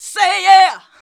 SAY YEAH 1.wav